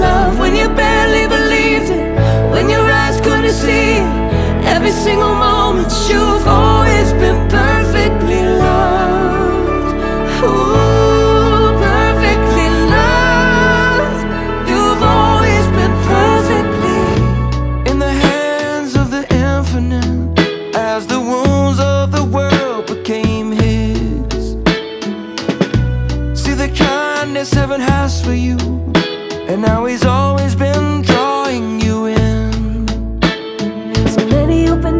# Christian & Gospel